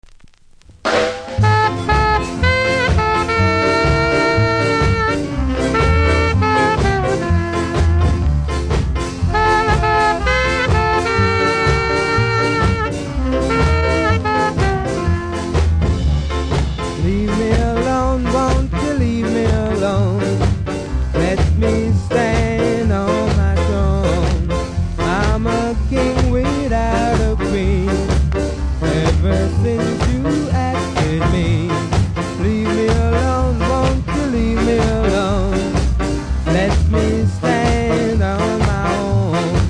見た目はキズ多いですがノイズはそれほど感じないので試聴で確認下さい。